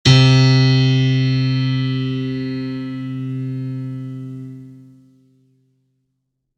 piano-sounds-dev
HardAndToughPiano
c2.mp3